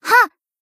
BA_V_Kotama_Camping_Battle_Shout_1.ogg